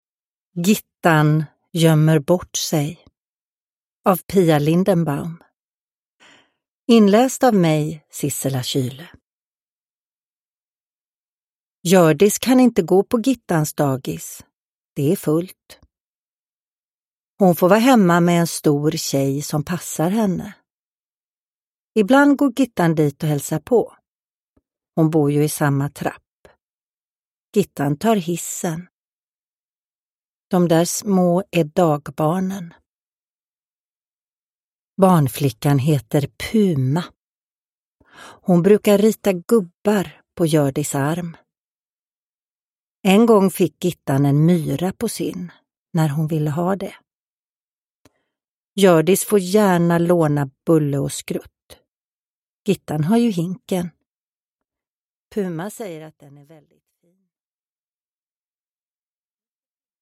Gittan gömmer bort sej – Ljudbok – Laddas ner
Uppläsare: Sissela Kyle